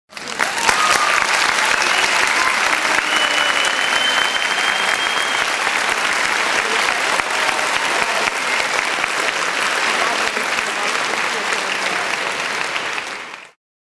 Ovacion - aplausos